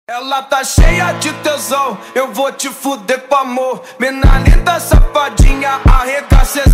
the Mp3 Sound Effect Lamborghini Aventador Ultimae — the final symphony of a V12 legend. Every rev, a heartbeat of history.